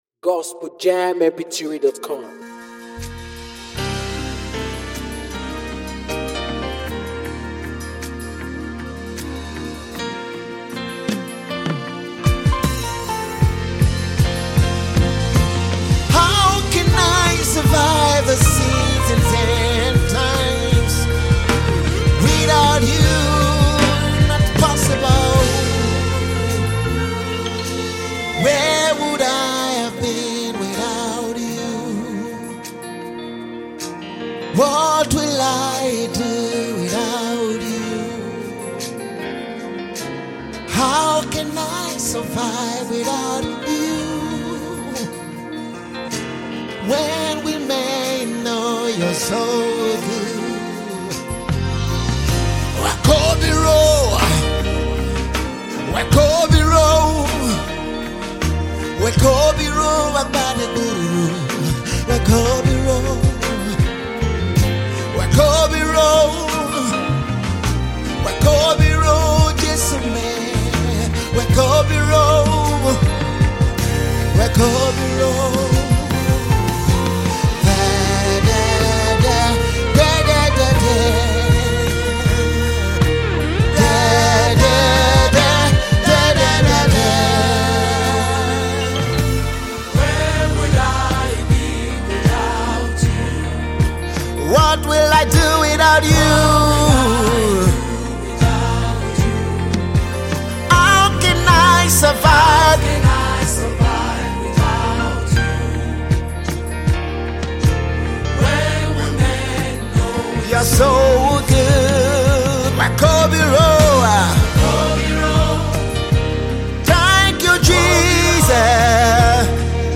NUMBER 1 AFRICA GOSPEL PROMOTING MEDIA
high spirit-filled a song